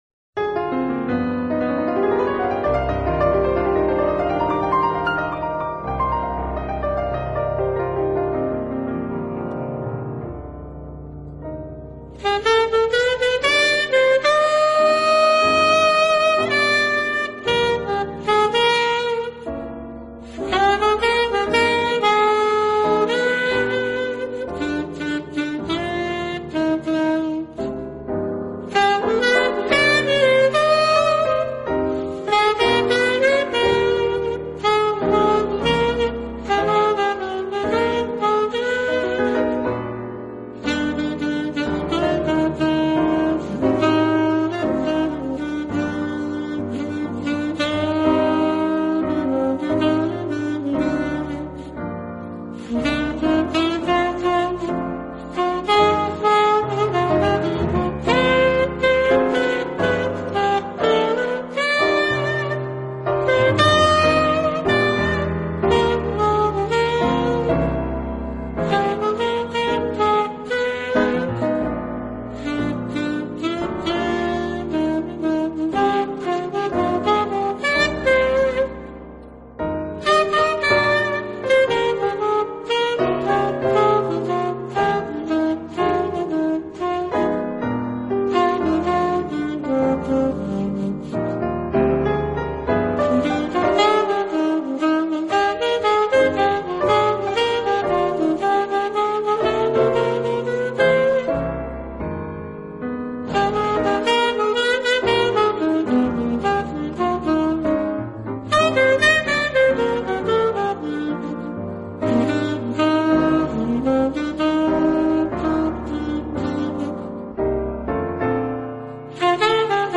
【爵士钢琴】
音乐类型：JAZZ